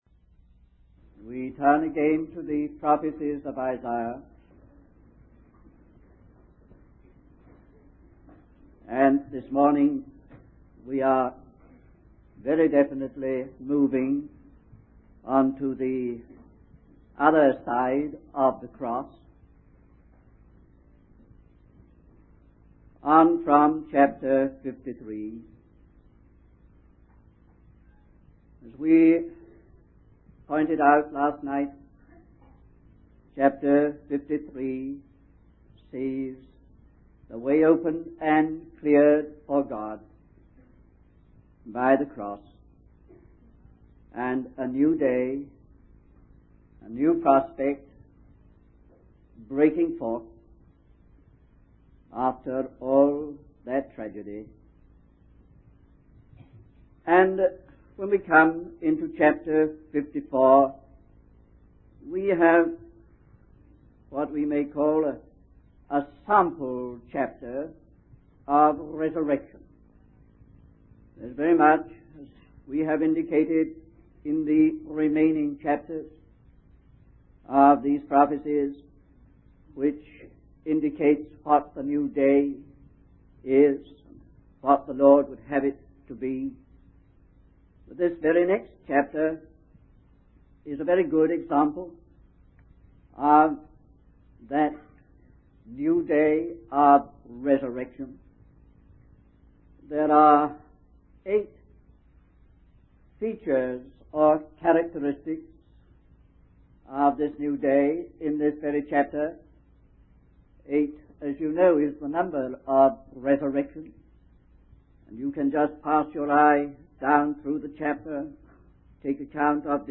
In this sermon, the preacher discusses the theme of resurrection and its significance in the Bible.